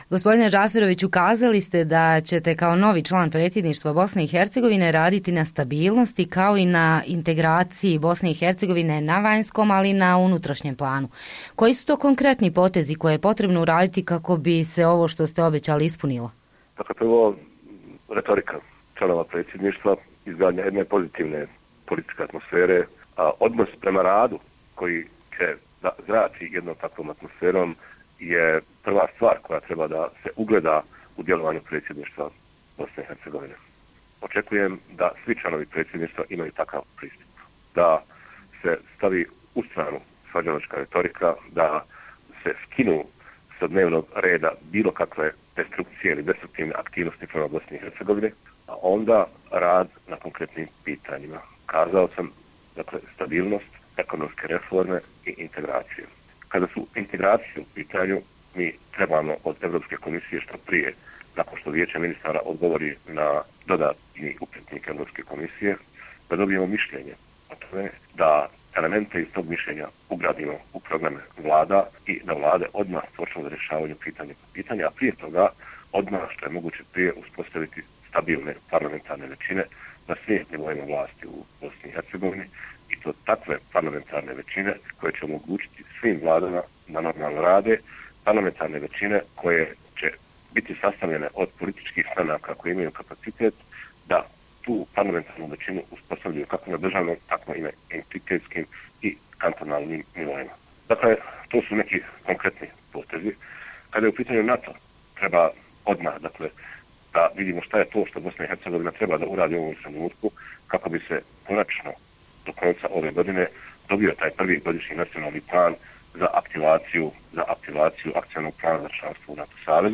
Intervju: Šefik Džaferović